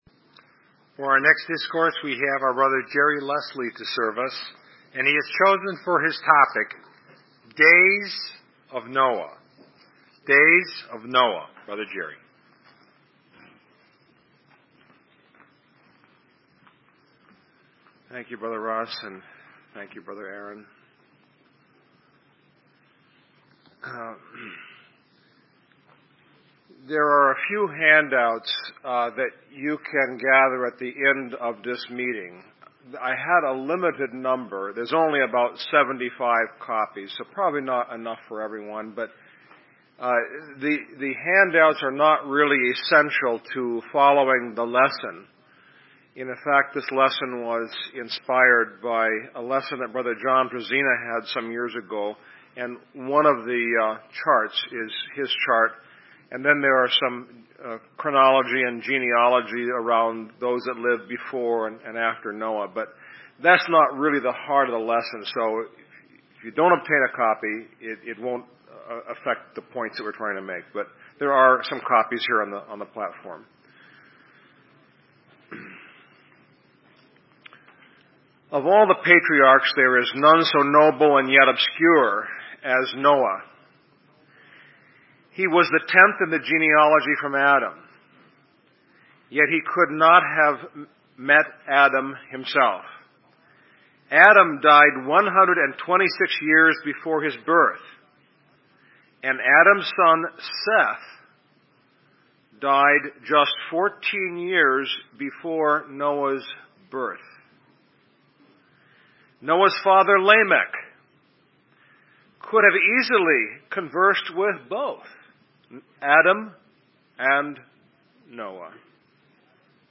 Index of /Chicago_Discourses/1993_Chicago_Memorial_Day_Convention